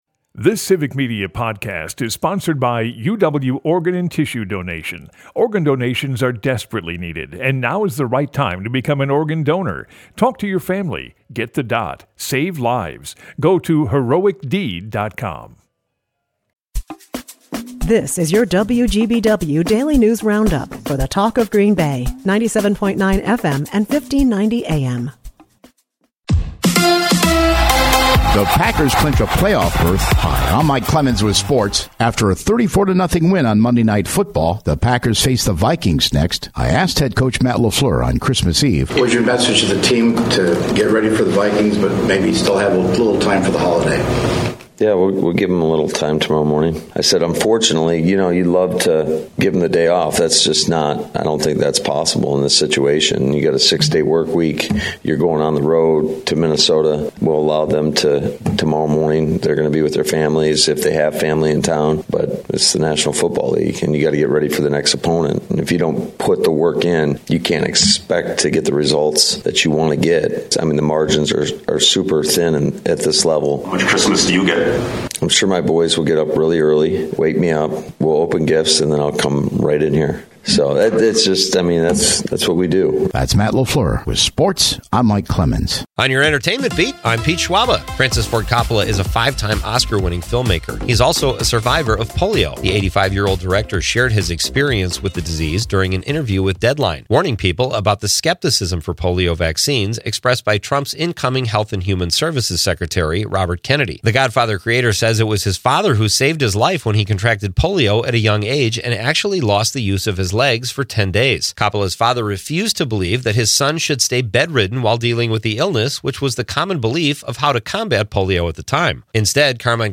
wgbw news